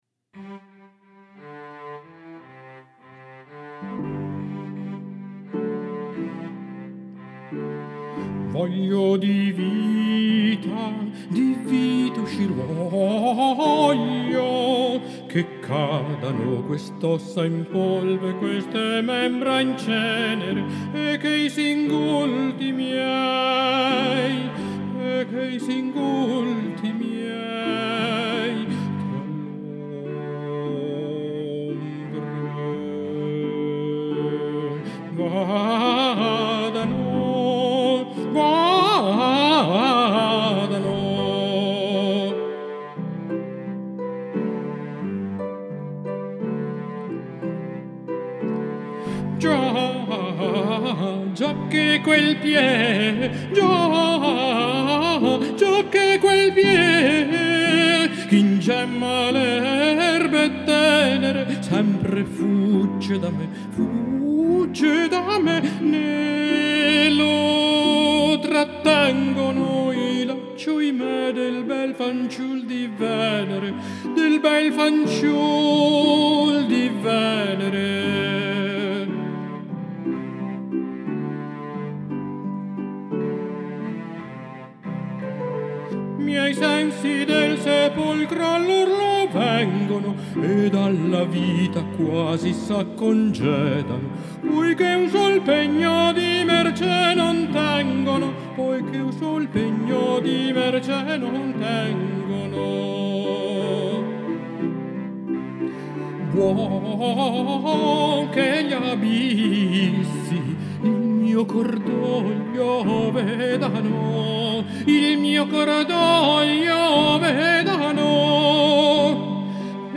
Mi sfogo qui, con una ciaccona di Zefiro, di Benedetto Ferrari, che son riuscito a costruire elettronicamente con Garage Band.